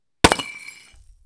concrete.wav